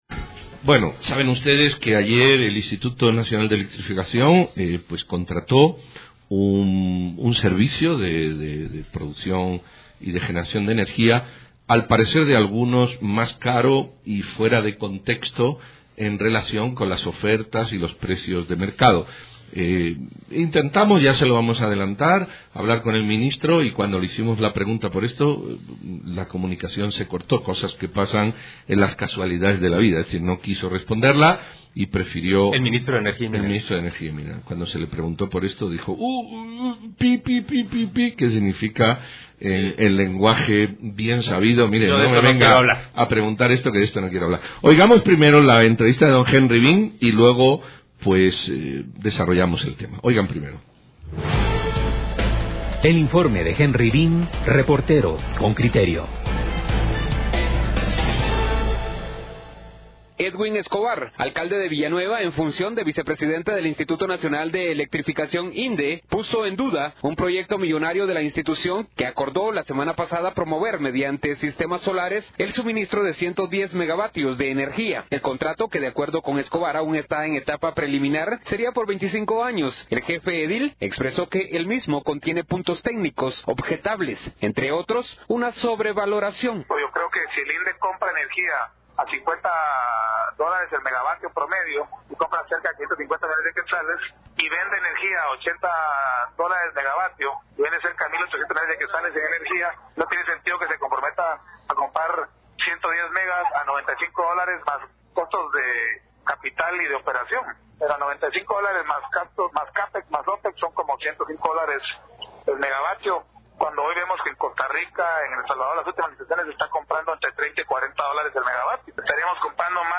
CON CRITERIO/RADIO INFINITA: Entrevista